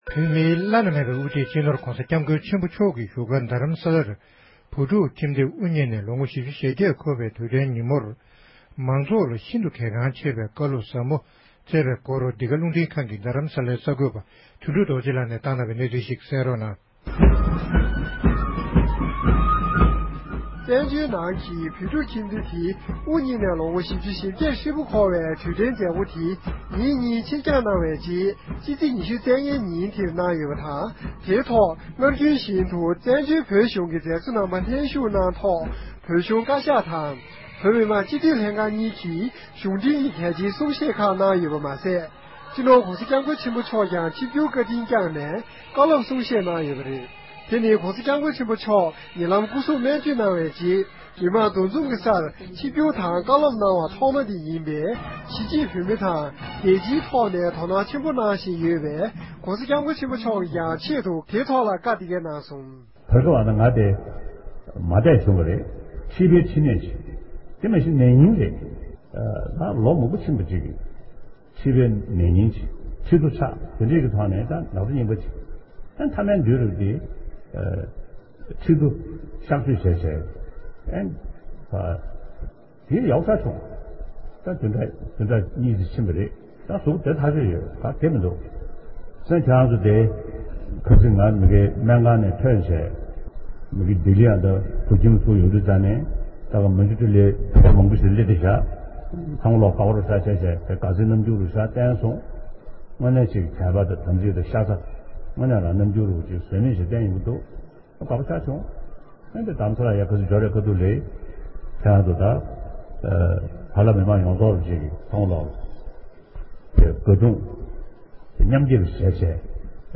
མཛད་སྒོའི་ཐོག་༸གོང་ས་༸སྐྱབས་མགོན་ཆེན་པོ་མཆོག་ནས་མང་ཚོགས་ལ་གལ་འགངས་ཆེ་བའི་བཀའ་སློབ་གསུང་བཤད་གནང་བ།